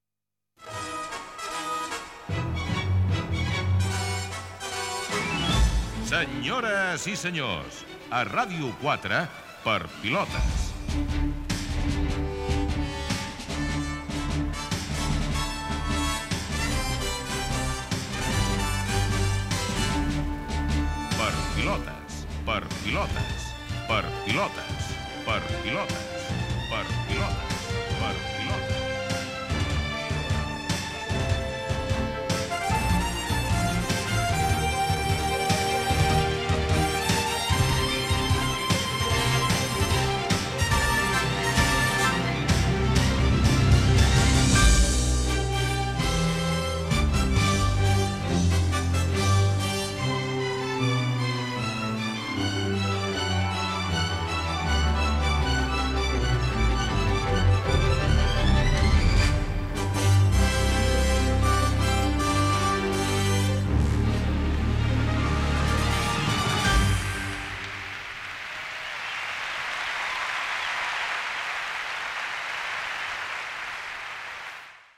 Careta del programa amb la sintonia d'entrada